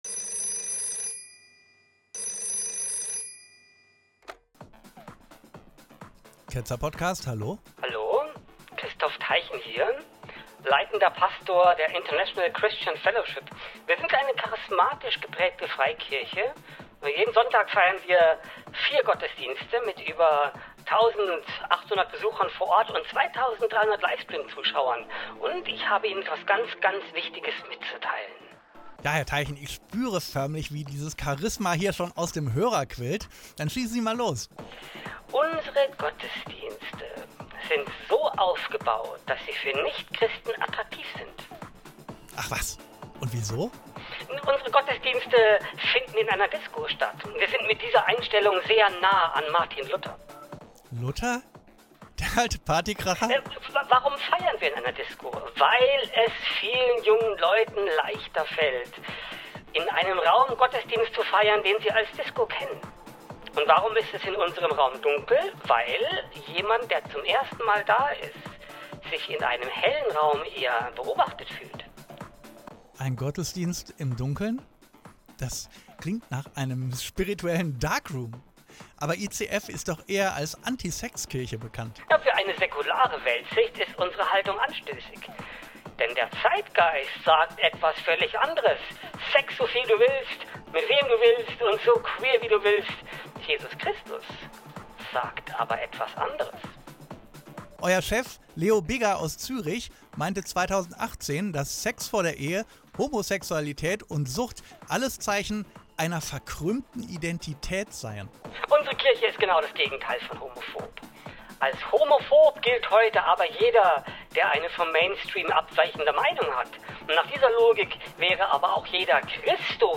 156.1 Dummer Anruf: Disco-Jesus gegen Hexenkult
156-1-Anruf-Disco-Jesus.m4a